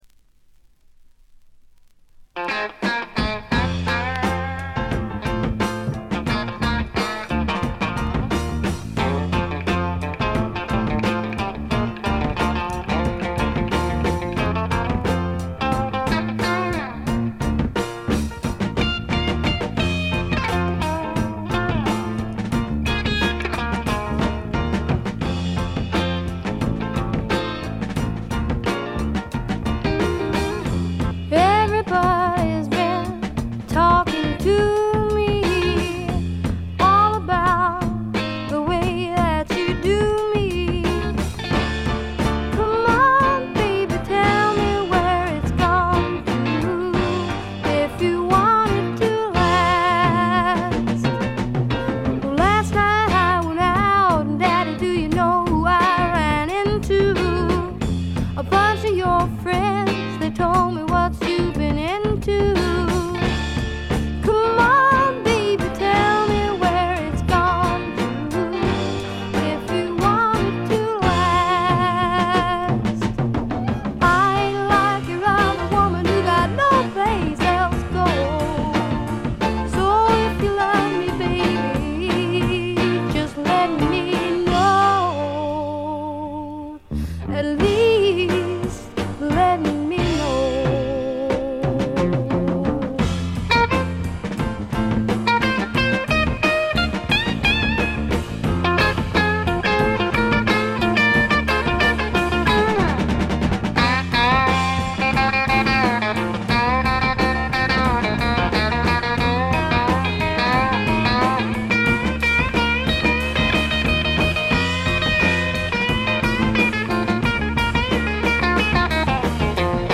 女性シンガーソングライター
バックがしっかり付いた重厚な音作りで、フォーキーな曲、アーシーな曲からハードな曲まで一気に聴かせます。
試聴曲は現品からの取り込み音源です。
Bass